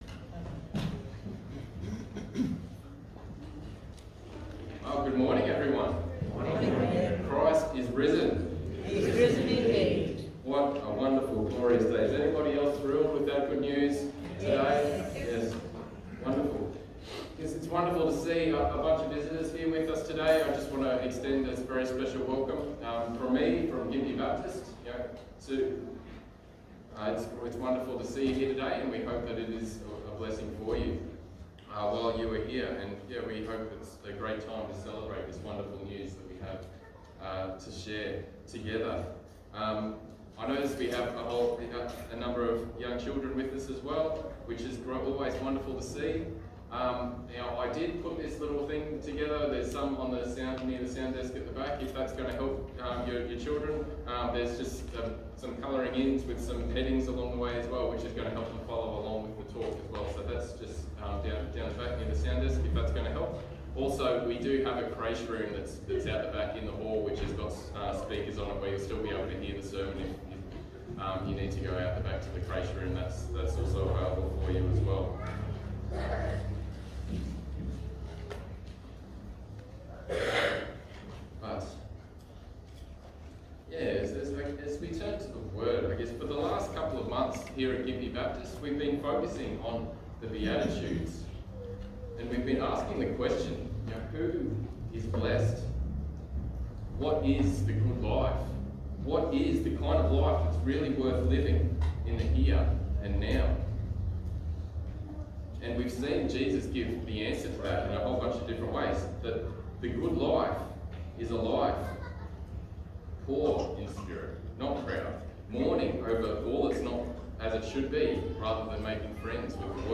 Passage: Matthew 5:3-11 Service Type: Sunday Morning « Blessed are the Peacemakers Abraham